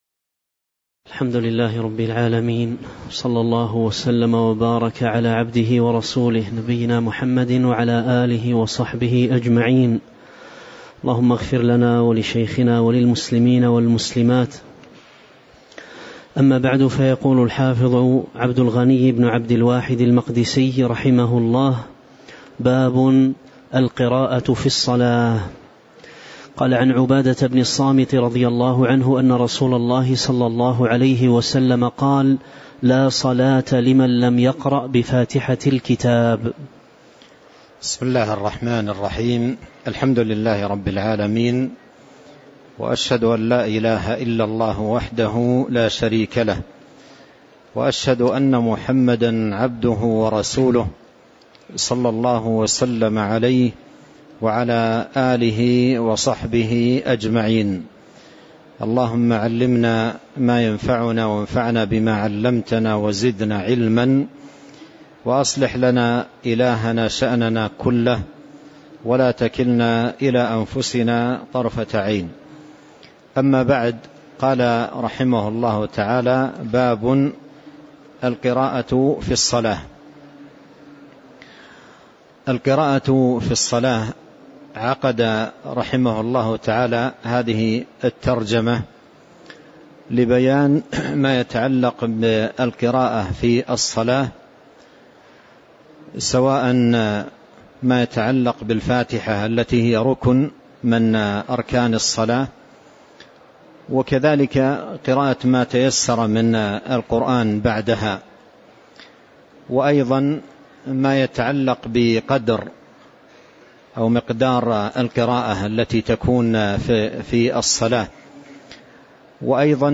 تاريخ النشر ٨ ربيع الثاني ١٤٤٤ هـ المكان: المسجد النبوي الشيخ